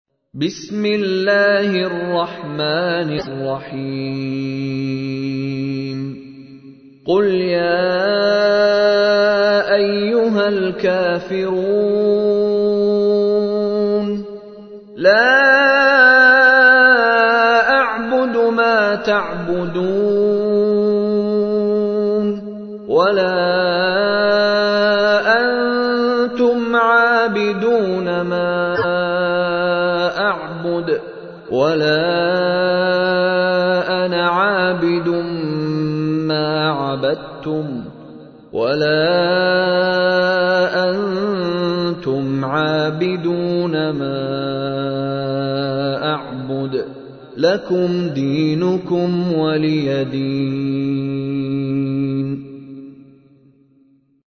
تحميل : 109. سورة الكافرون / القارئ مشاري راشد العفاسي / القرآن الكريم / موقع يا حسين